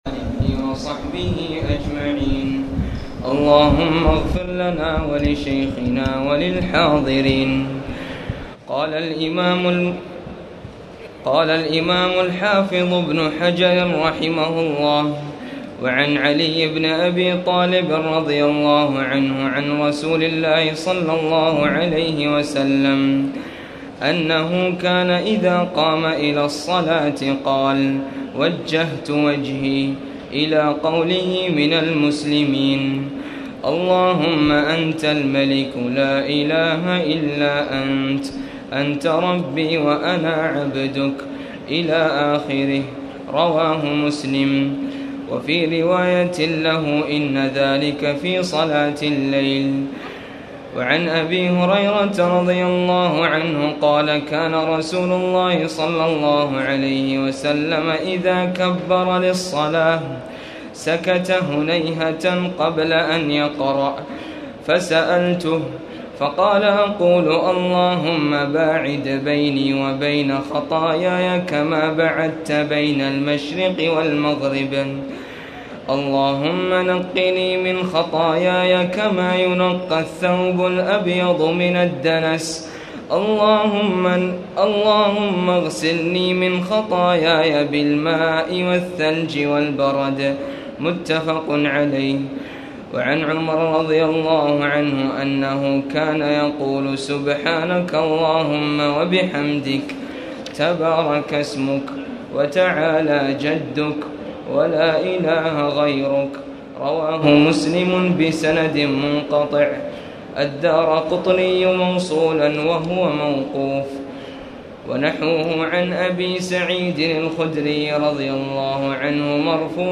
تاريخ النشر ٤ رمضان ١٤٣٨ هـ المكان: المسجد الحرام الشيخ: فضيلة الشيخ أ.د. خالد بن عبدالله المصلح فضيلة الشيخ أ.د. خالد بن عبدالله المصلح باب في صفة الصلاة